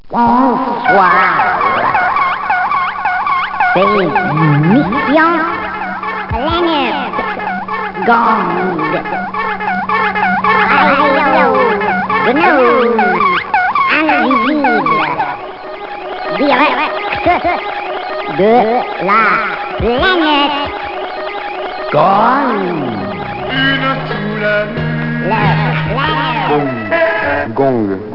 1 channel
GONG.mp3